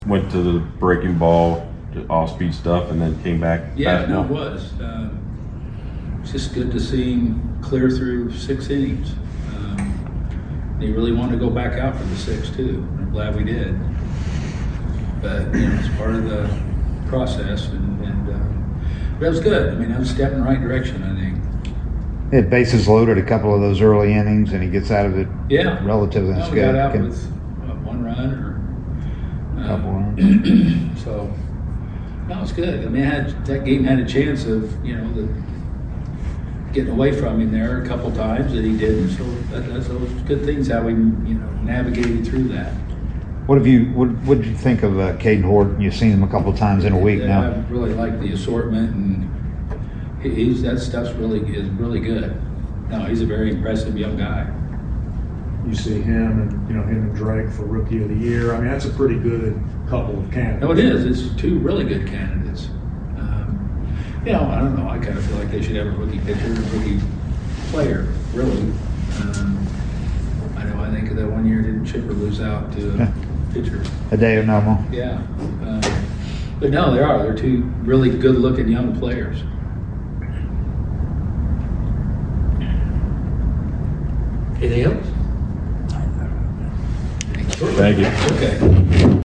Atlanta Braves Manager Brian Snitker Postgame Interview after losing to the Chicago Cubs at Truist Park.